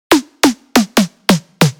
How To Create a Snare in Sylenth1 (Preset Download)